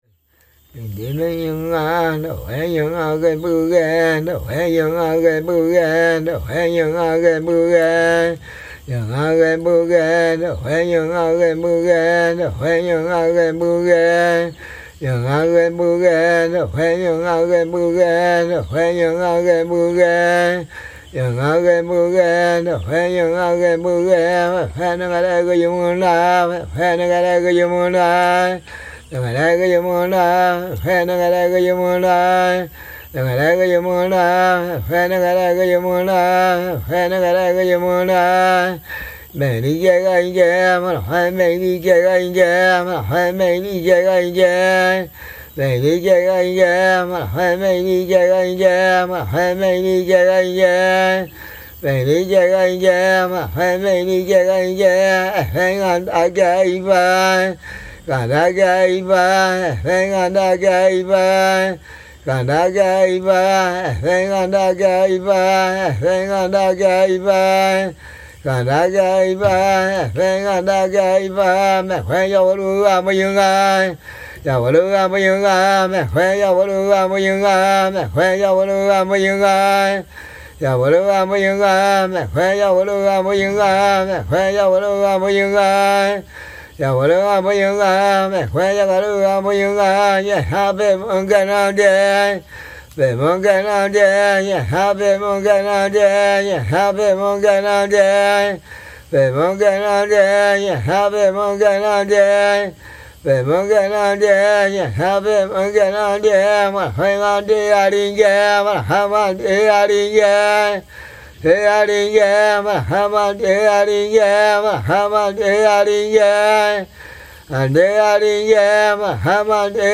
This recording captures the early morning sounds of an ancient Waorani warrior in the Ecuadorian Amazon. Staying with him in his traditional palm-thatched longhouse, the tranquility of the environment was profound, with minimal external noise.
At dawn, as we emerged from our hammocks, I asked if I could record him.